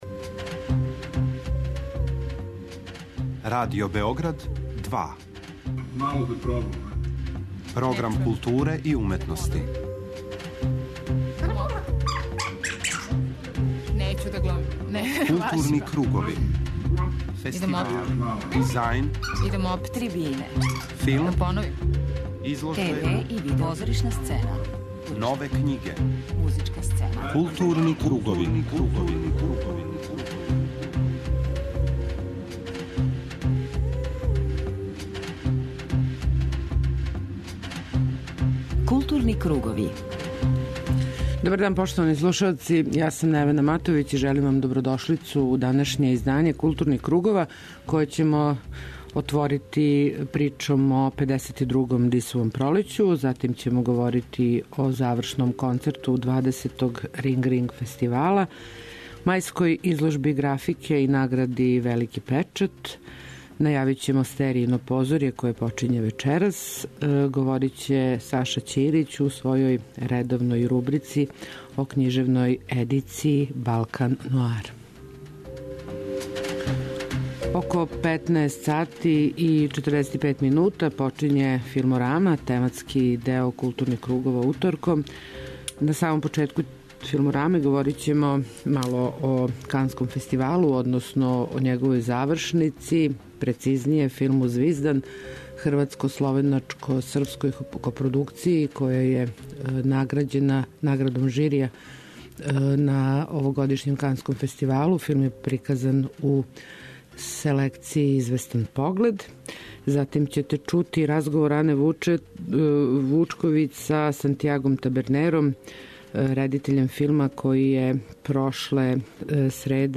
У данашњој емисији чућете разговор
преузми : 53.72 MB Културни кругови Autor: Група аутора Централна културно-уметничка емисија Радио Београда 2.